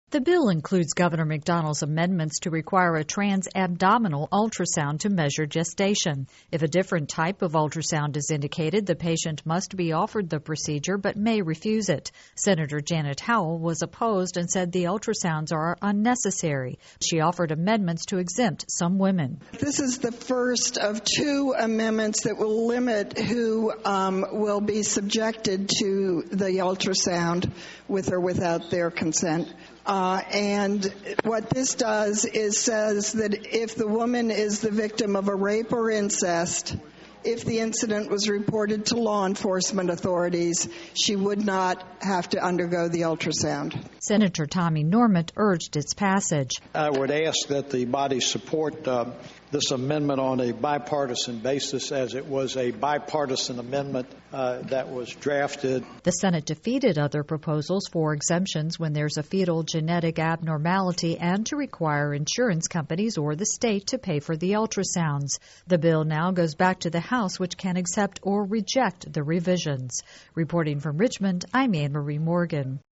This entry was posted on February 28, 2012, 4:22 pm and is filed under Daily Capitol News Updates.